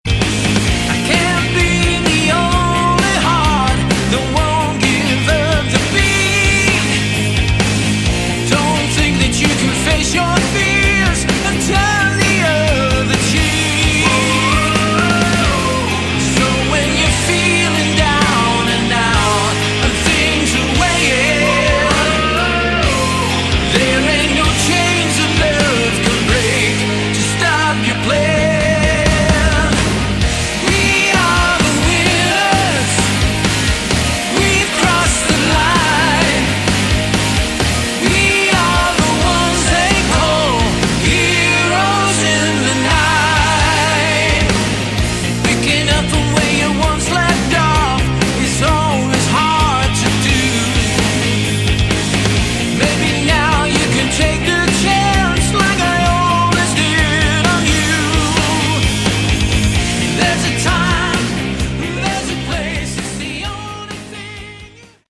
Category: Melodic Rock
guitar
vocals
bass
keyboards
drums